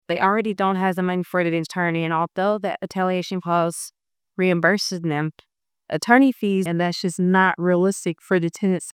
The Human Rights Commission of Hopkinsville–Christian County hosted its second public forum on the Uniform Residential Landlord–Tenant Act.